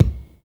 X KICK 2.wav